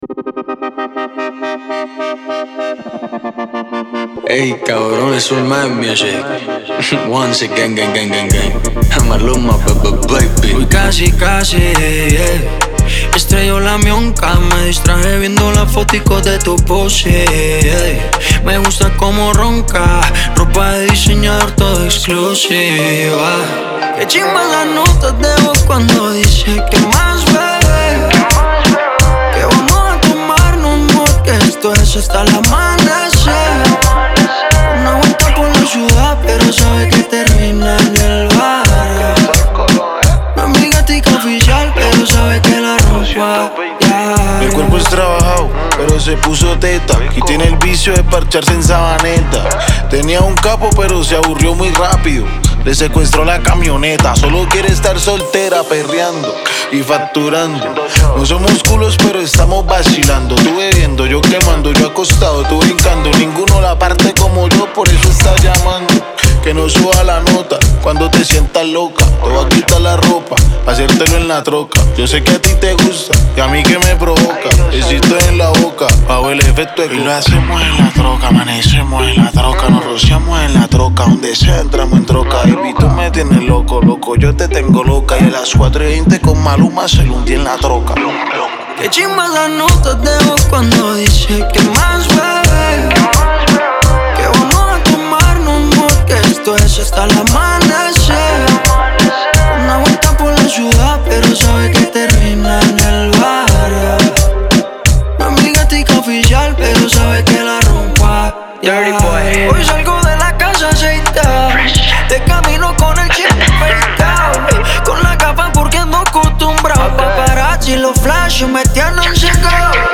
سبک لاتین
Latin Music